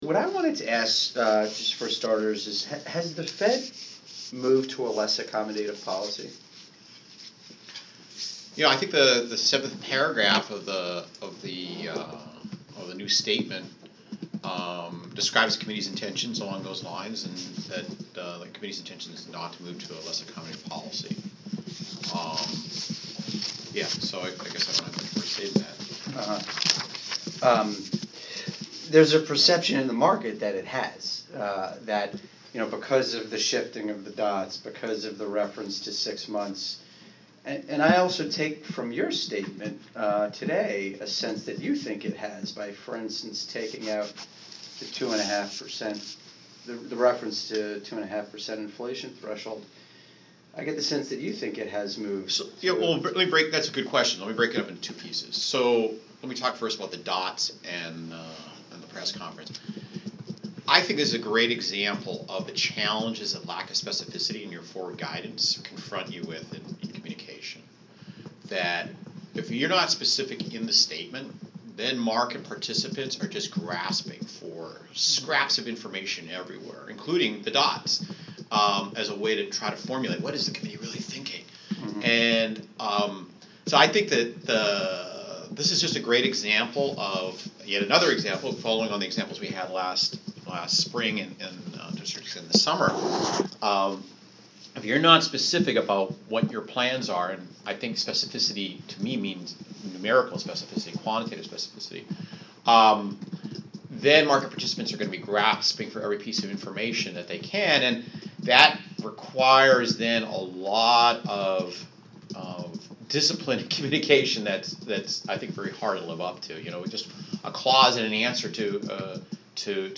Interview with The Wall Street Journal on March 21st (Audio) | Federal Reserve Bank of Minneapolis
Narayana Kocherlakota interview with The Wall Street Journal on March 21, 2014.